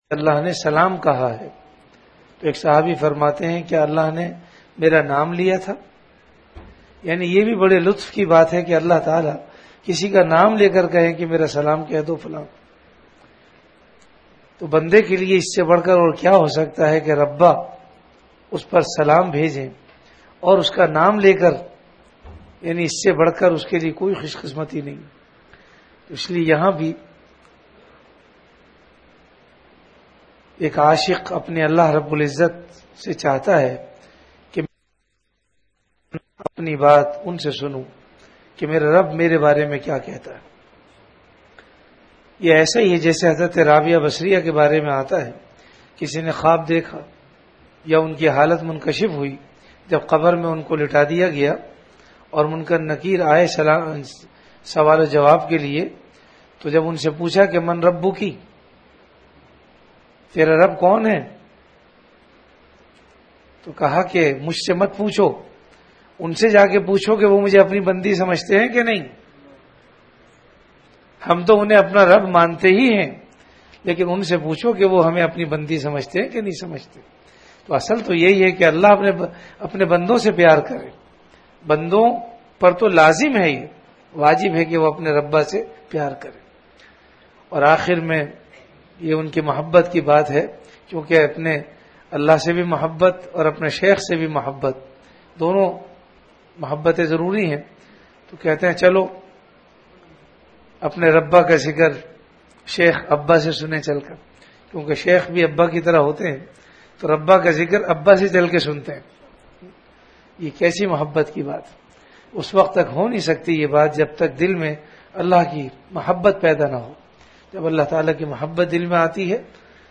Delivered at Home.
Majlis-e-Zikr · Home Majlis e Zkir(Sun-25Apr2010